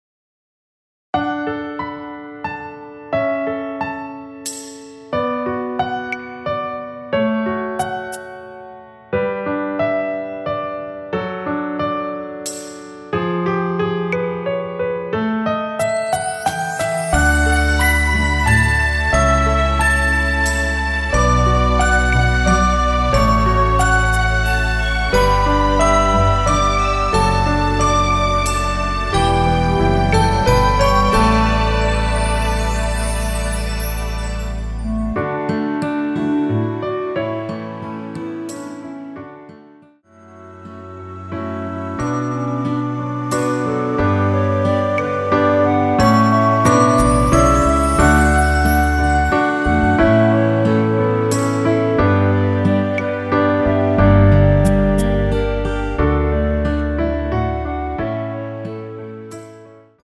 원키 멜로디 포함된 MR입니다.
멜로디 MR이란
앞부분30초, 뒷부분30초씩 편집해서 올려 드리고 있습니다.